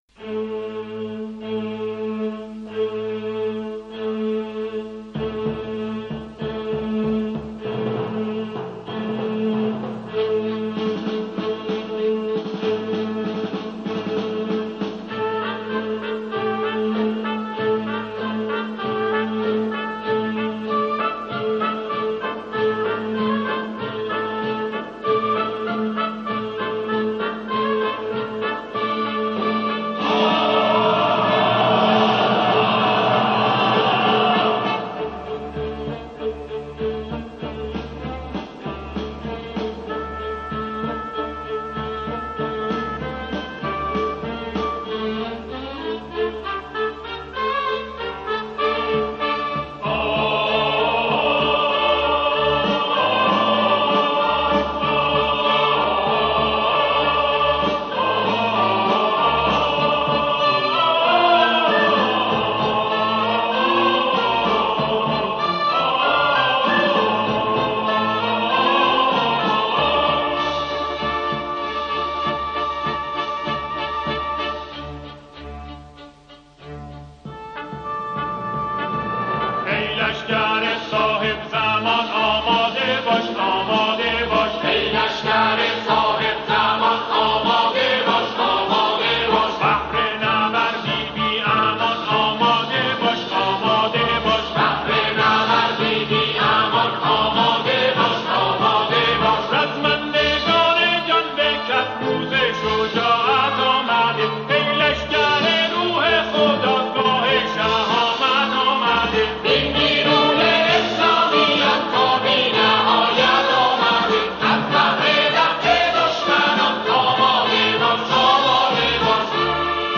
آنها در این قطعه، شعری را درباره دفاع مقدس همخوانی می‌کنند.
برای اجرای ارکسترال تنظیم شده است